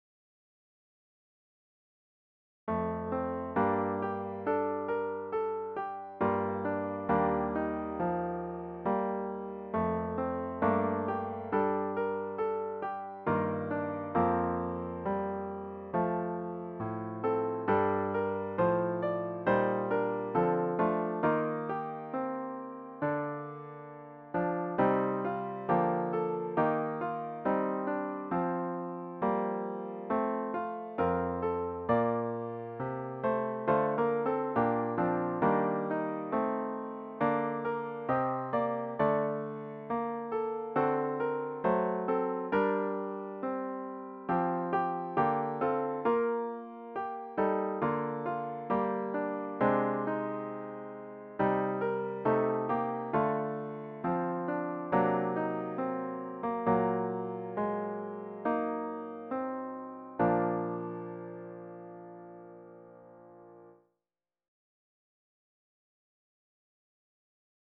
The hymn should be performed at a sustained♩ = ca. 68.